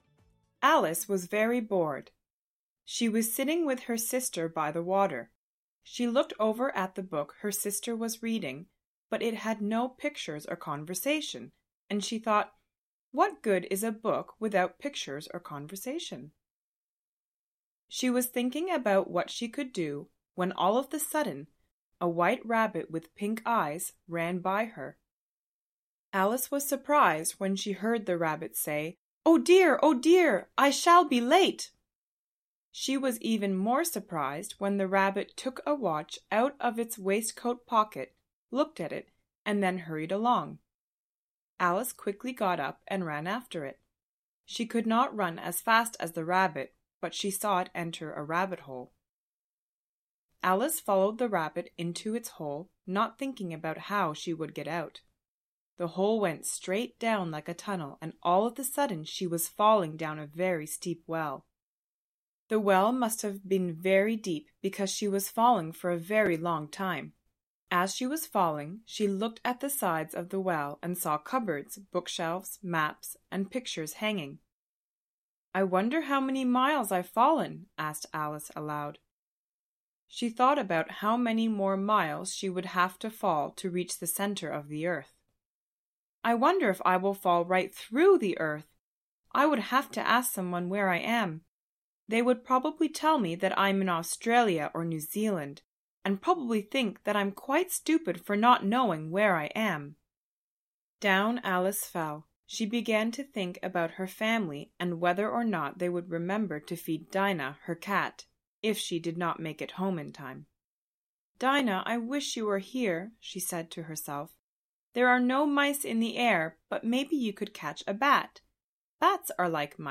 Audio kniha
Ukázka z knihy
Naše zjednodušená verze je určena pro středně pokročilé, kteří si nejsou hádankami a jinotaji, jež obsahuje originál, zcela jisti. Audio verzi namluvili rodilí mluvčí.